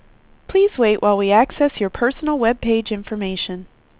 WindowsXP / enduser / speech / tts / prompts / voices / sw / pcm8k / pwpcarrier_9.wav